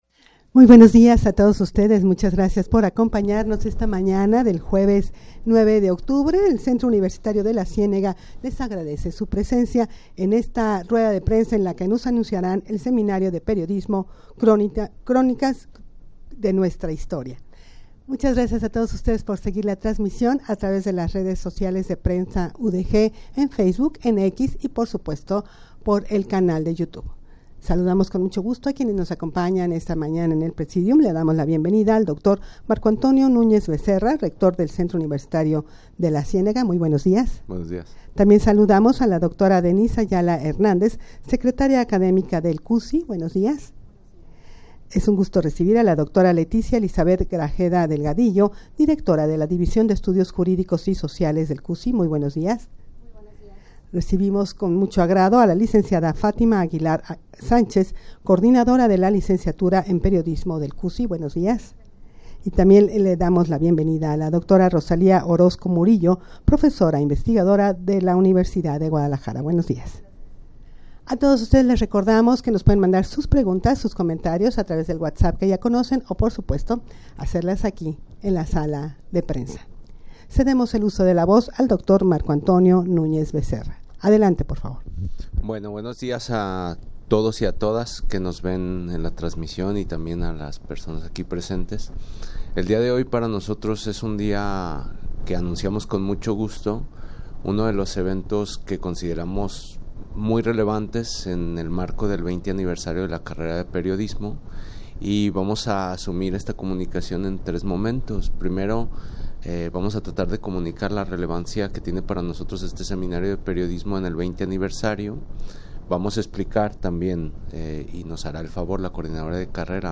Audio de la Rueda de Prensa
rueda-de-prensa-para-anunciar-el-seminario-de-periodismo-cronistas-de-nuestra-historia.mp3